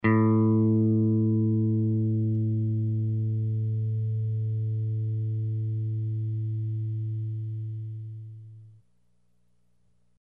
Descarga de Sonidos mp3 Gratis: afinar guitarra cuerda cinco.
guitarra-afinar-cuerda-cinco-.mp3